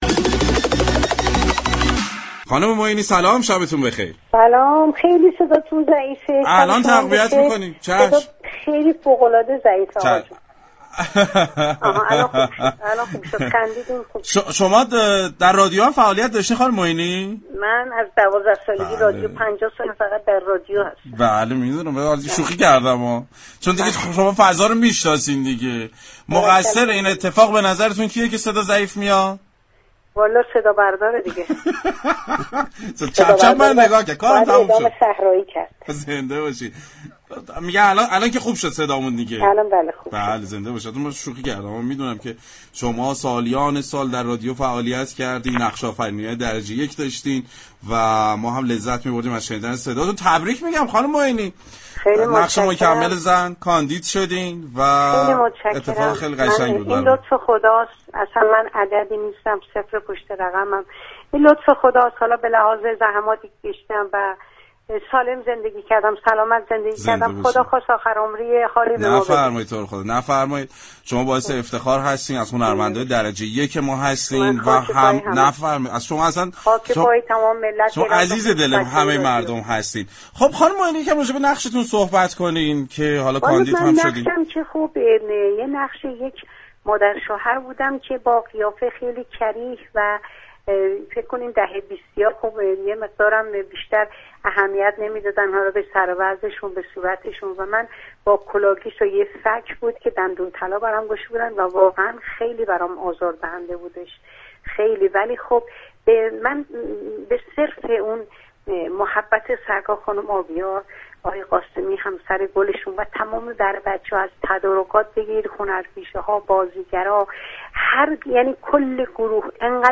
به گزارش پایگاه اطلاع رسانی رادیو تهران، گیتی معینی بازیگر معروف كشورمان در گفتگوی تلفنی با برنامه صحنه درباره نامزدی دریافت سیمرغ بهترین بازیگر نقش مكمل زن در سی و نهمین جشنواره فیلم فجر گفت: این لطف خداوند است.